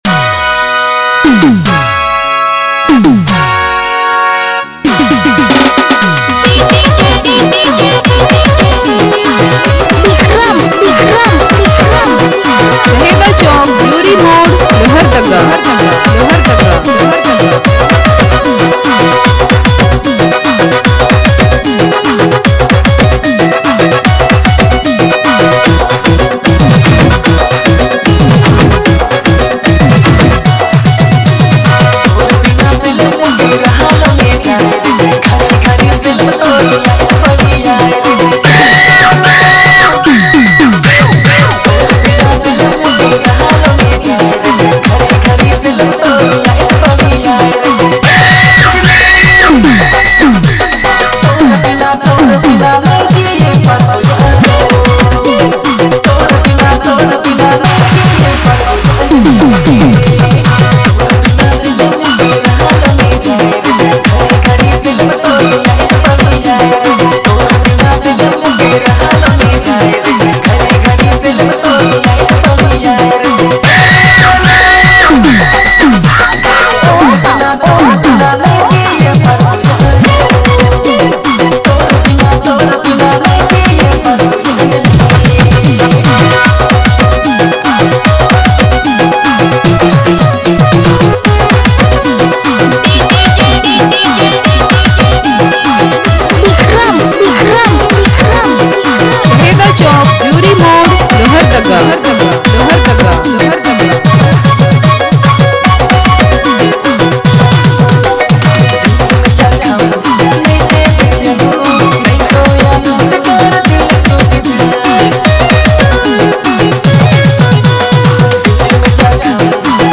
heart-touching Nagpuri DJ song